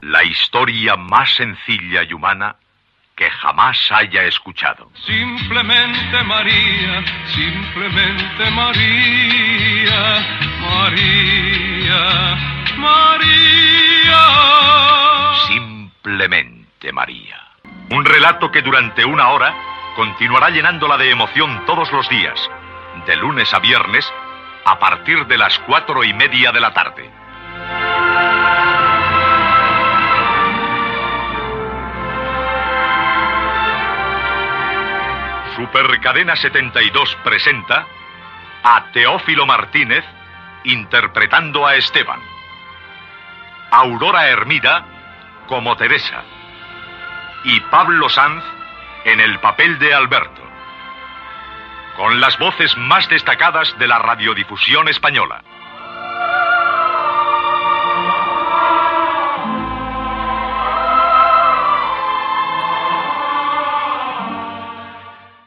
Careta del serial amb el repartiment.
Ficció